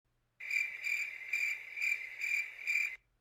Bad Joke Crickets
bad-joke-crickets.mp3